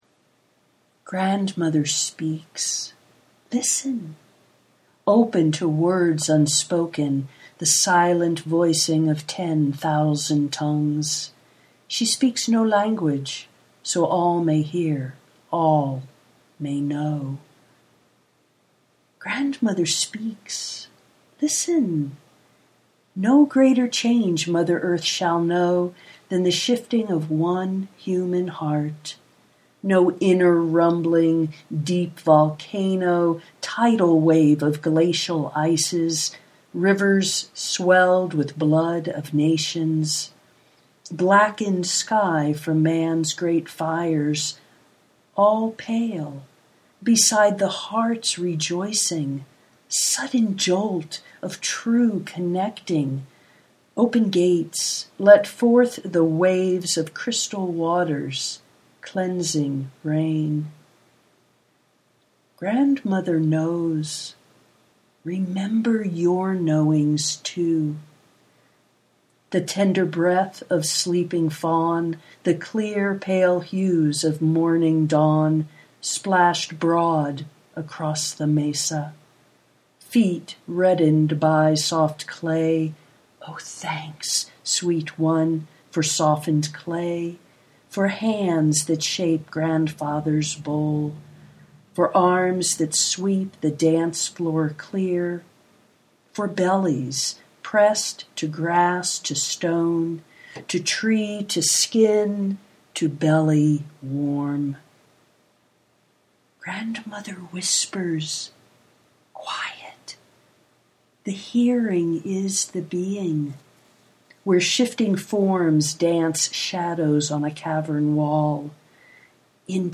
morning of the letting go (audio poetry 4:25)